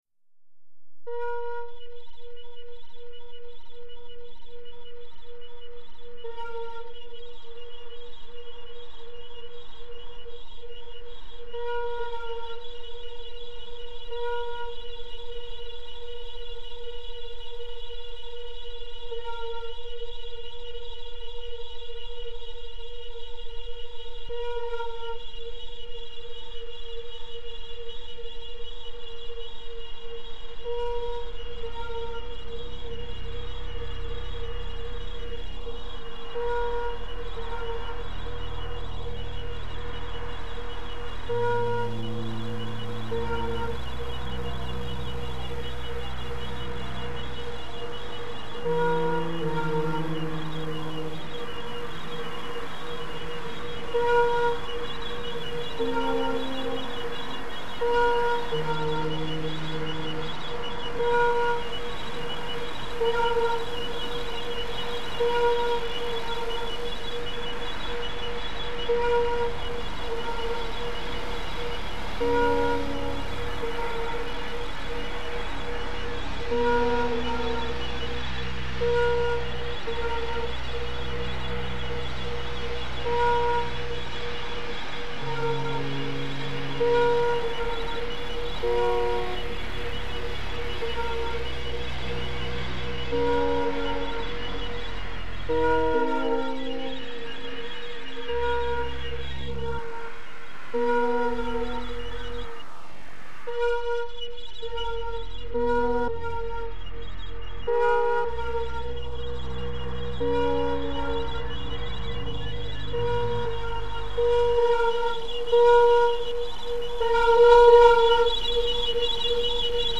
Синтезатор "Kurzweil", 1996, 2008 гг.